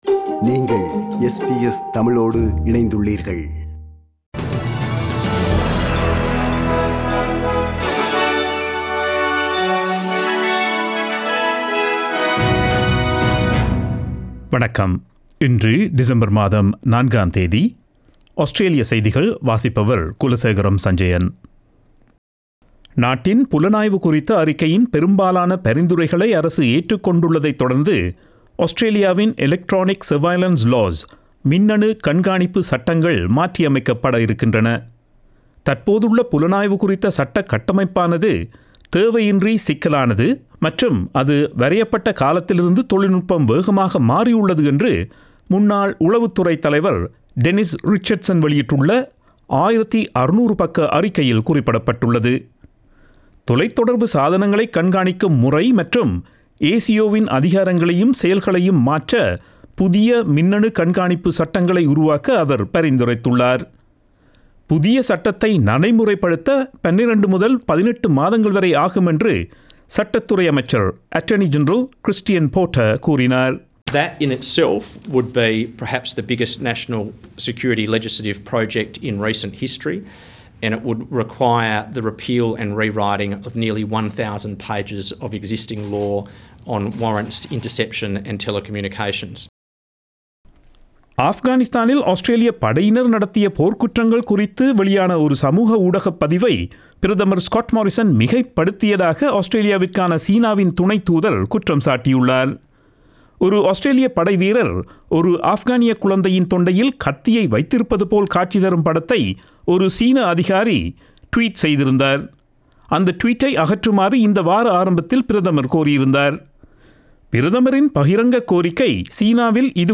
Australian news bulletin for Friday 04 December 2020.